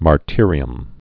(mär-tîrē-əm)